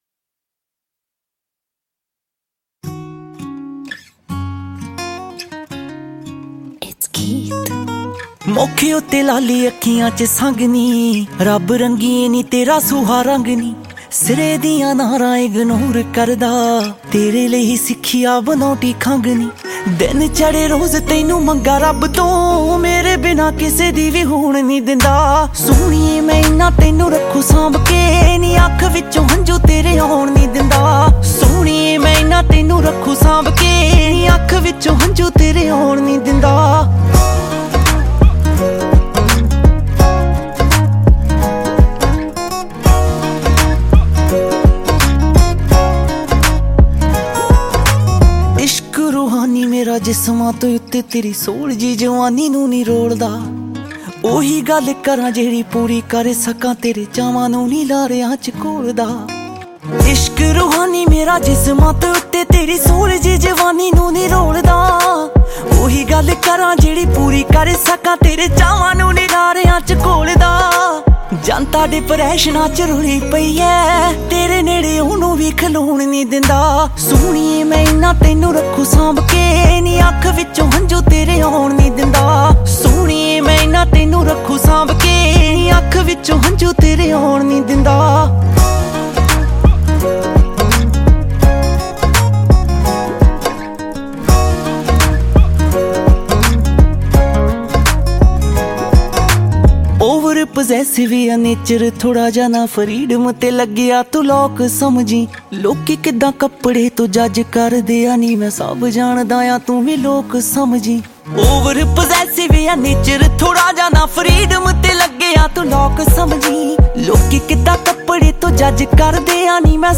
Releted Files Of Latest Punjabi Song